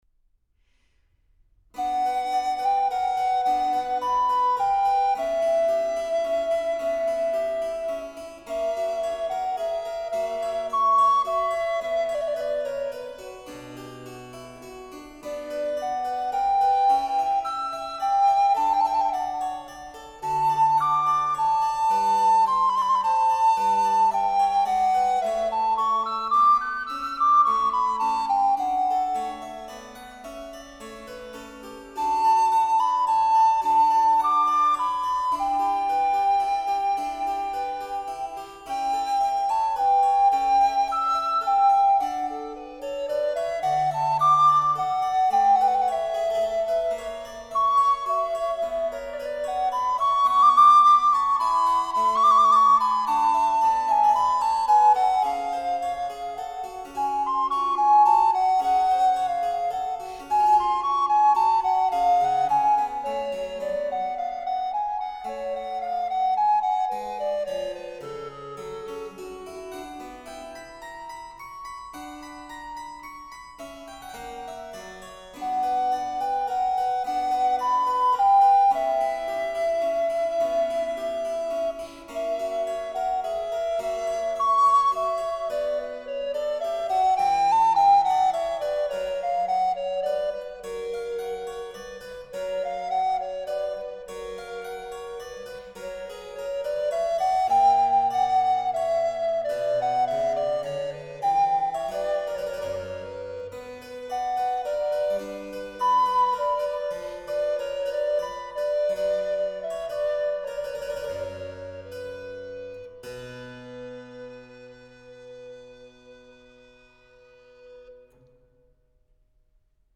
A01-06 Sonata in E-flat_ Siciliano | Miles Christi